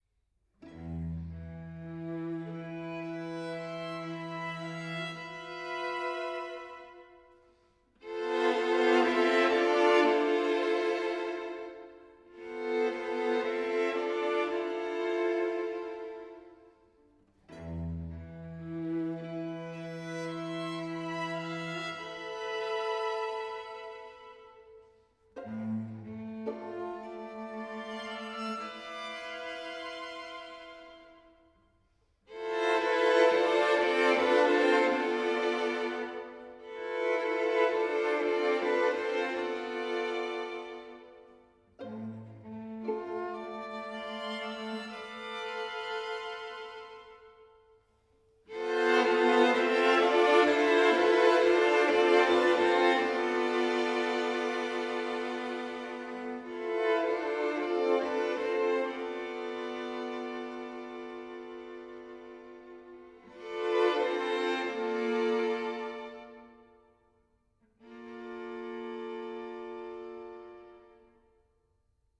Strijkkwartet fragmenten